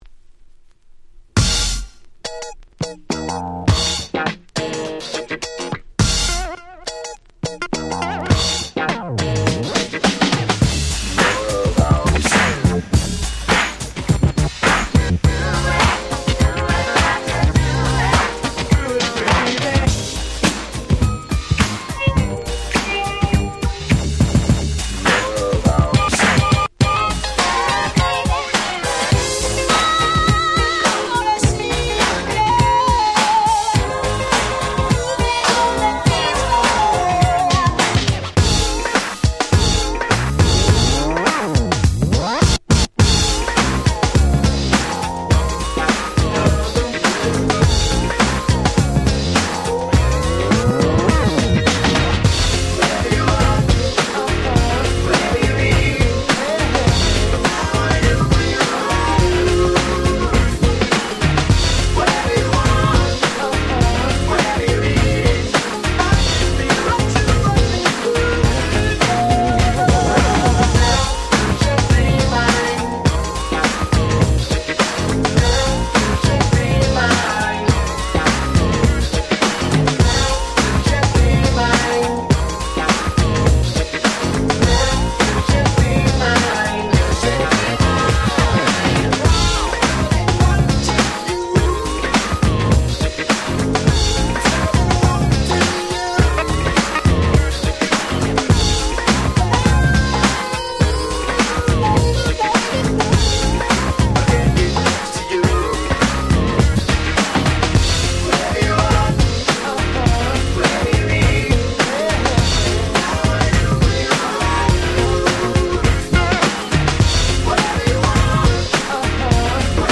勝手にリミックス キャッチー系